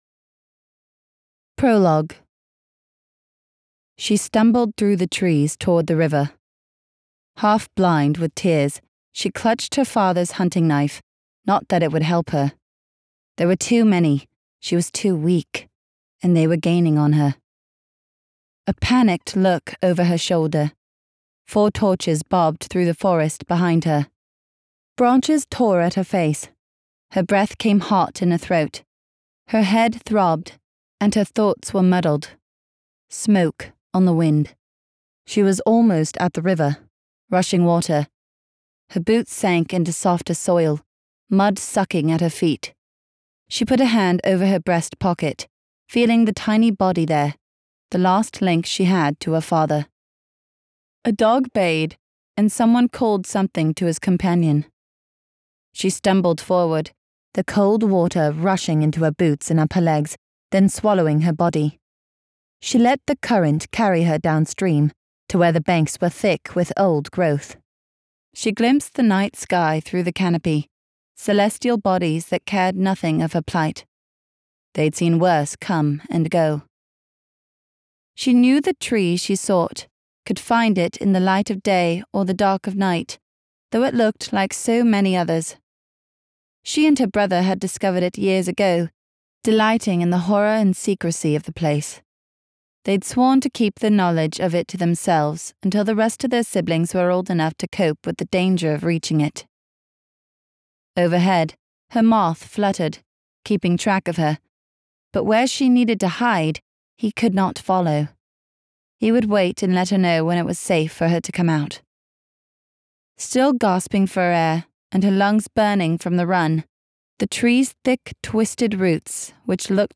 Book Three | Excerpt